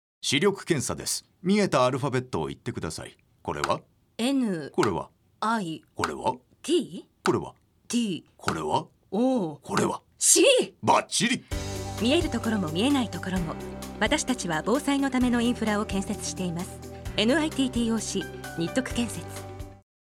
・ラジオCM「視力検査編」